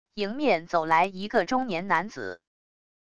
迎面走来一个中年男子wav音频生成系统WAV Audio Player